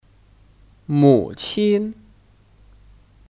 母亲 (Mǔqīn 母亲)